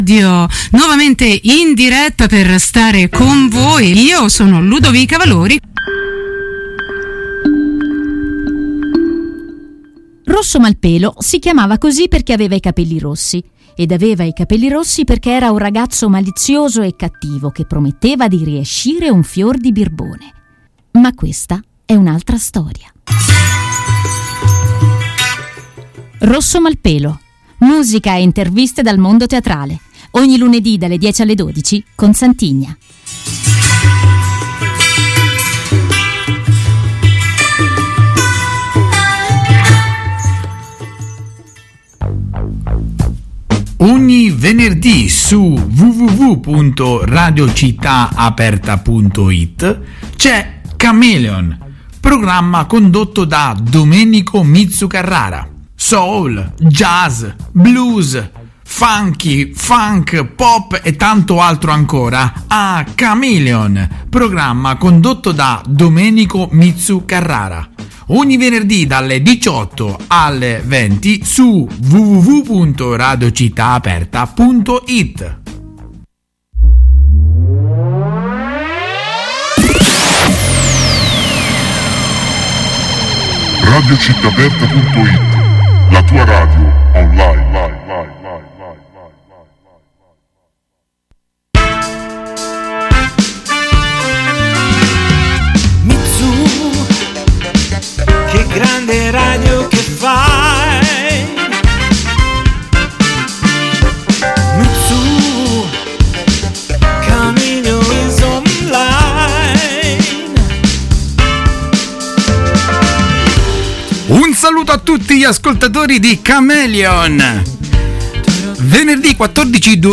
Una gradevole chiacchierata